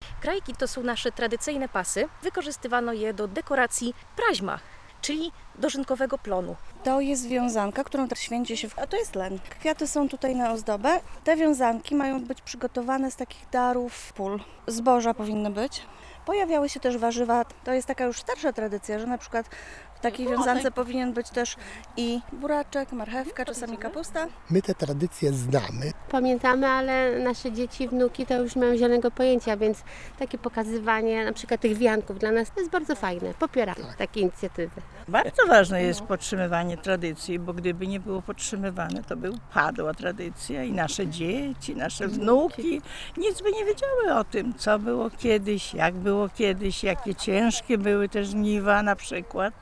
Jak wyglądały dawniej żniwa? Tego można było się dowiedzieć w niedzielę (13.07) w Podlaskim Muzeum Kultury Ludowej w Wasilkowie.